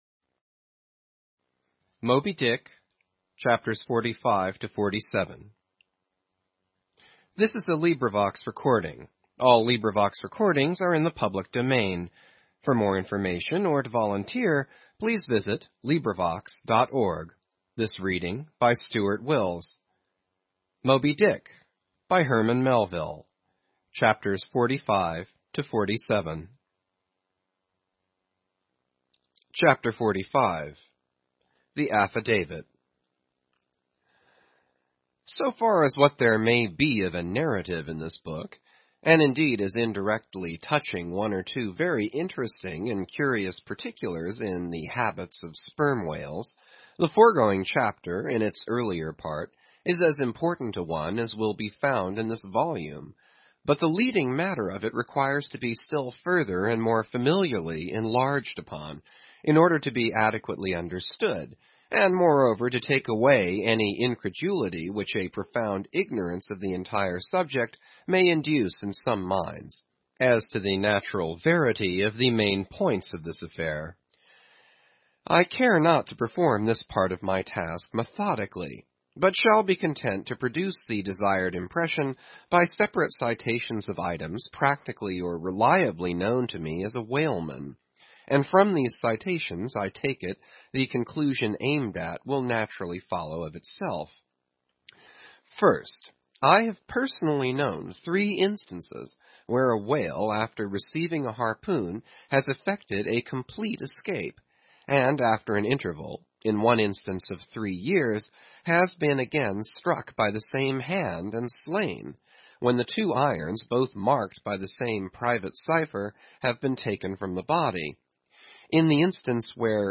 英语听书《白鲸记》第475期 听力文件下载—在线英语听力室